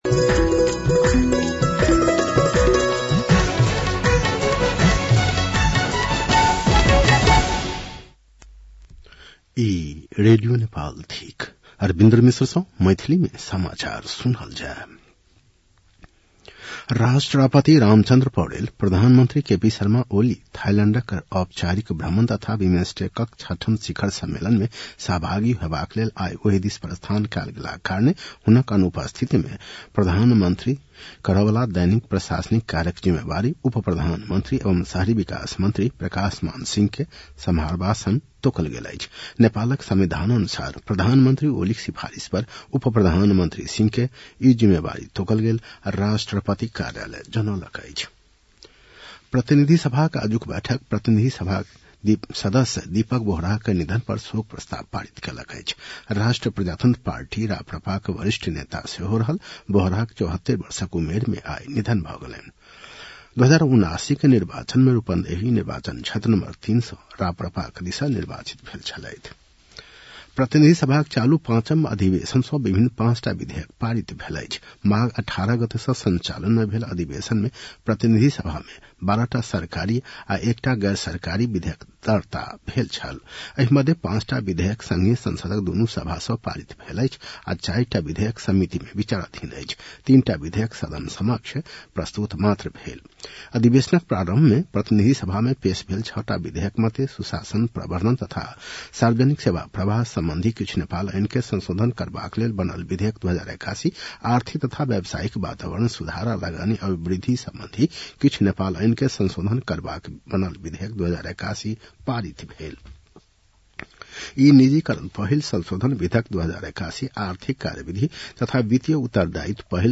An online outlet of Nepal's national radio broadcaster
मैथिली भाषामा समाचार : १९ चैत , २०८१